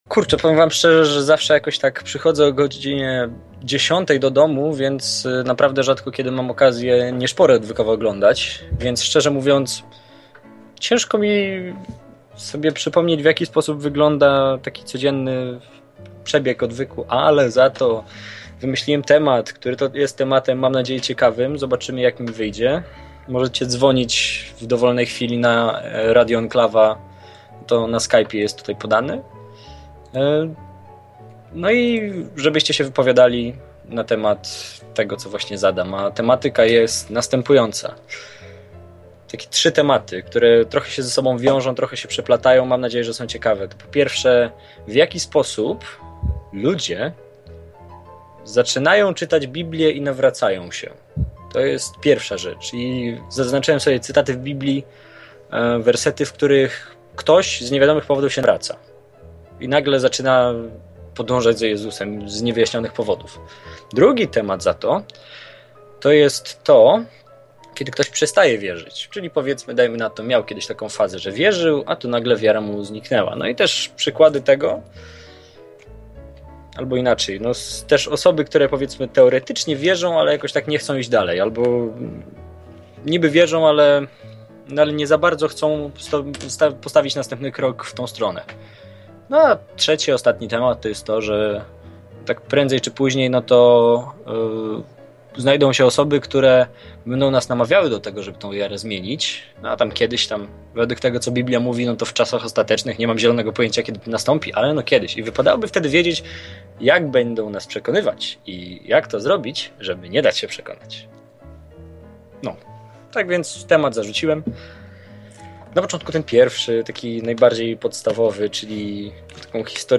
Dziś dla odmiany zupełnie nowy prowadzący. Opowiada o ludziach, co kiedyś byli chrześcijanami, ale odeszli.